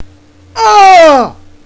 So in my vast amount free time, i have recorded myself saying a number of things retardely.
Weird noise
aah.wav